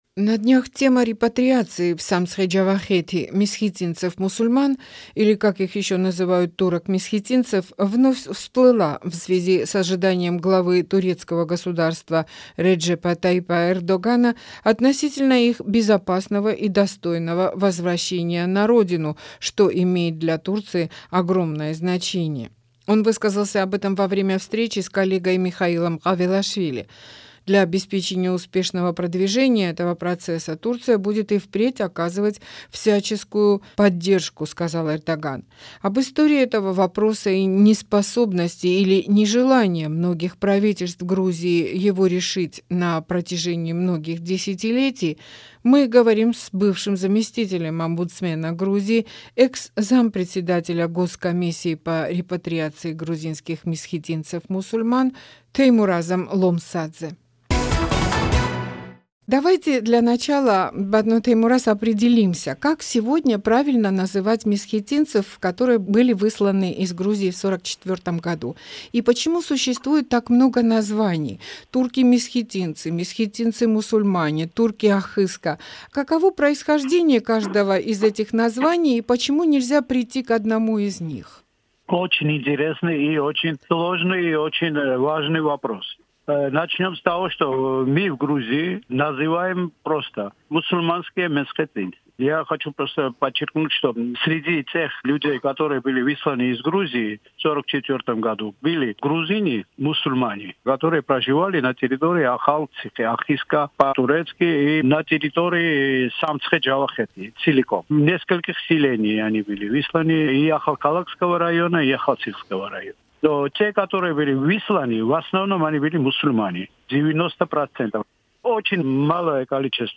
Рубрика "Гость недели", беседы с политиками, экспертами, общественными деятелями